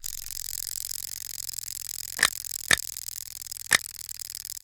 SA_bite.ogg